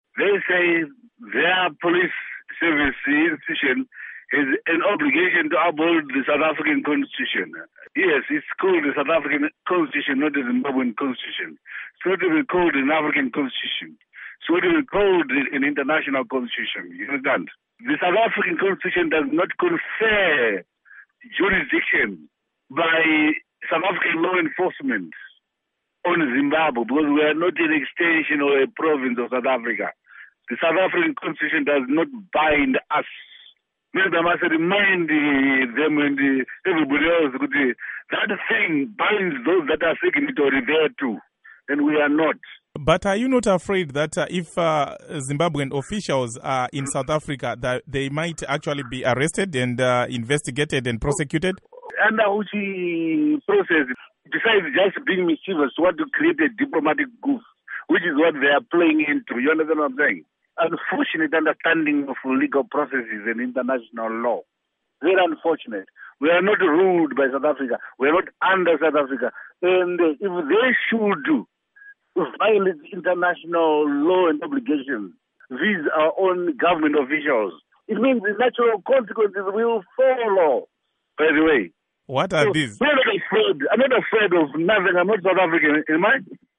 Interview With Johannes Tomana